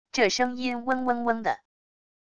这声音嗡嗡嗡的wav音频